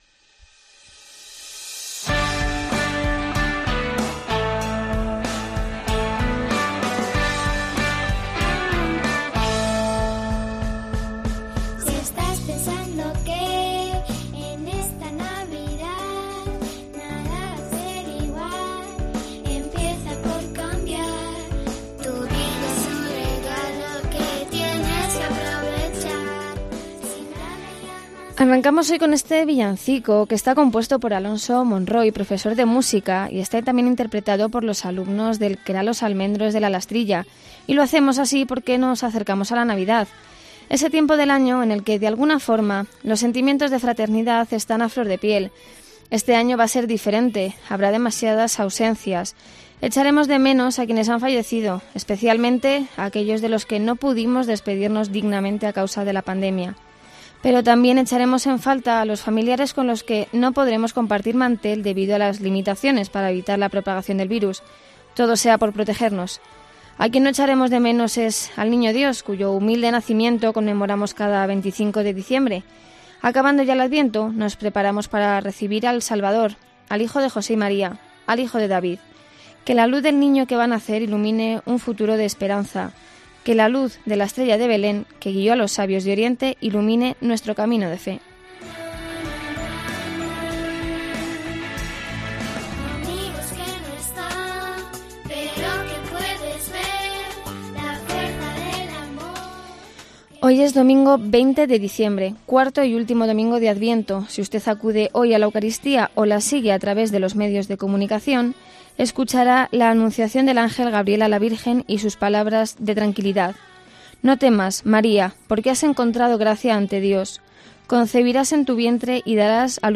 Programa semanal de información cristiana